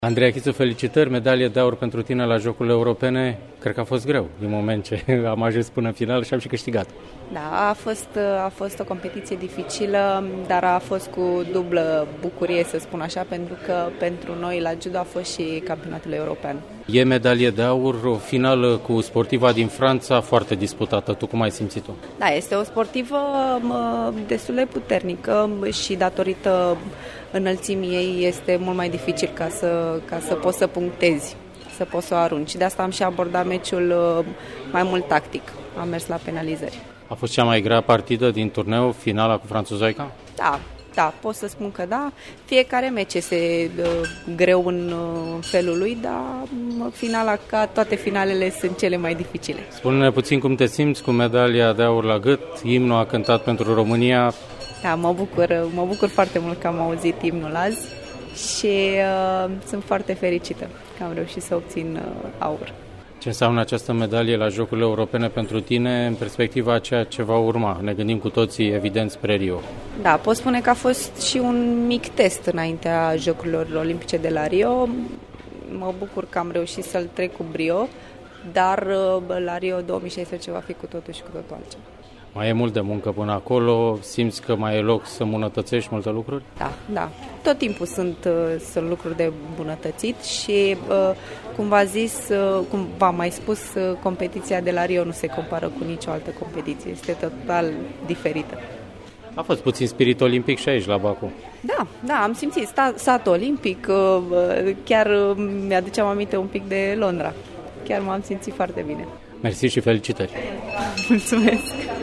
Andreea Chițu a declarat după finală că bucuria victoriei e dublă, întrucât competiția de judo de la Baku contează și drept Campionat European.